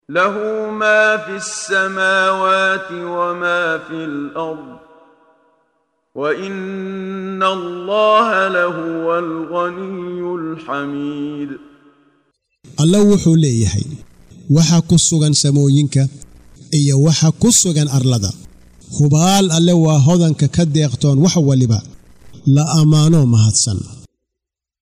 Waa Akhrin Codeed Af Soomaali ah ee Macaanida Suuradda Al-Xaj ( Xajka ) oo u kala Qaybsan Aayado aha